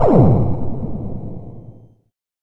GUNFIRE.mp3